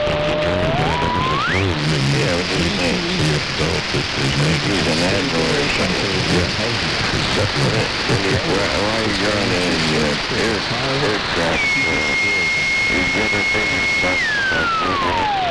hf-radio-static.wav